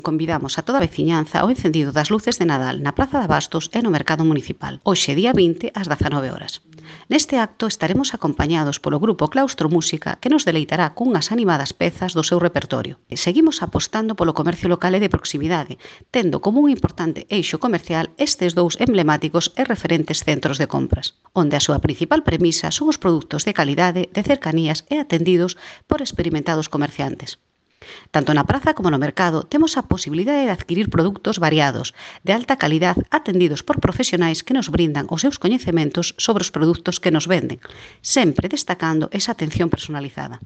Audio A concelleira de Participación e Servizos para a Veciñanza, Cristina López, sobre o acendido das luces | Descargar mp3